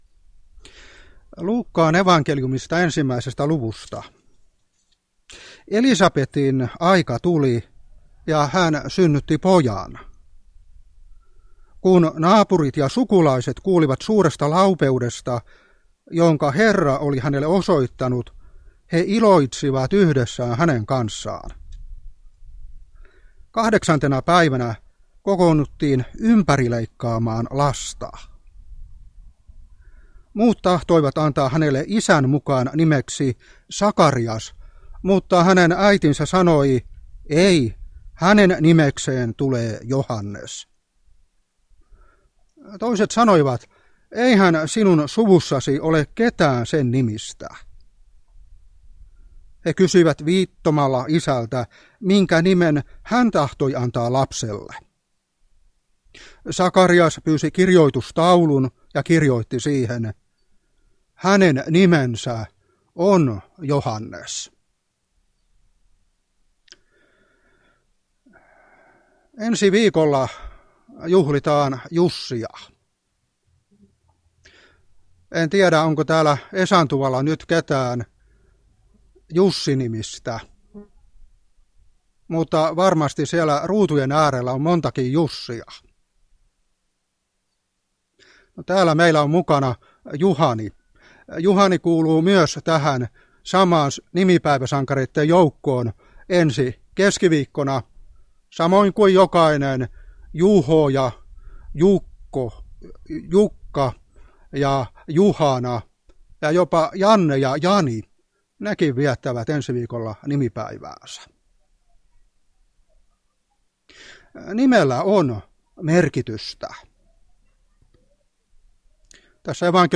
Lapuan Kauhajärvi
Esantuvan juhannusjuhlassa